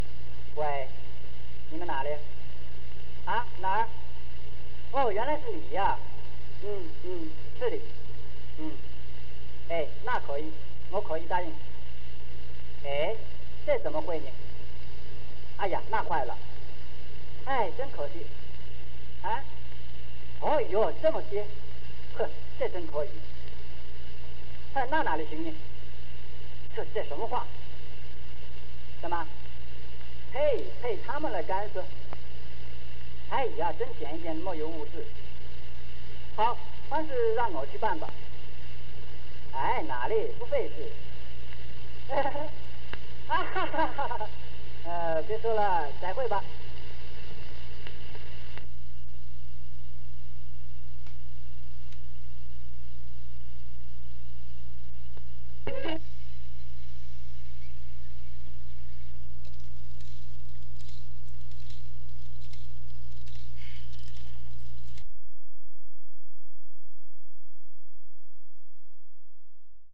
《国语留声片课本》赵元任朗读“感叹词” - 六边形